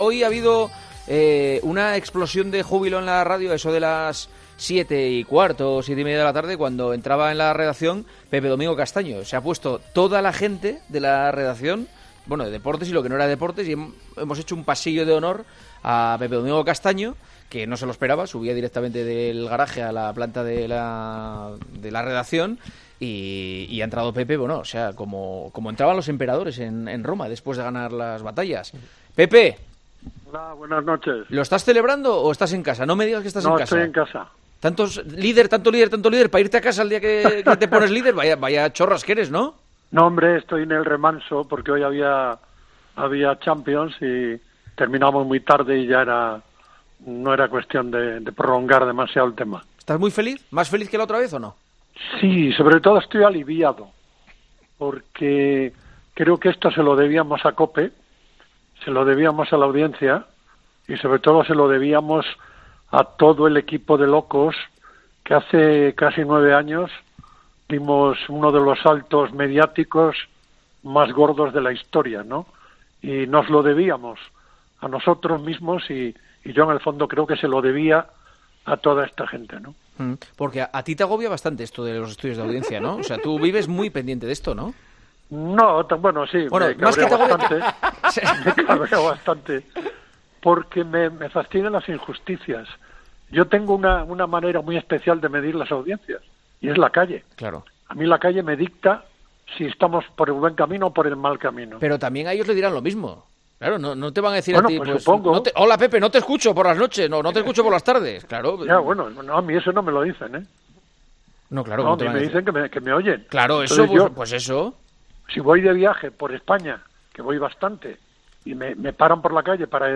El histórico 10 de abril de 2019, en que el Estudio General de Medios situó a Tiempo de Juego líder absoluto de la radio deportiva los fines de semana, Pepe Domingo Castaño aprovechó su intervención, esa noche, en El Partidazo de COPE, para confirmar que seguirá una temporada más en la Cadena COPE, junto a Paco González.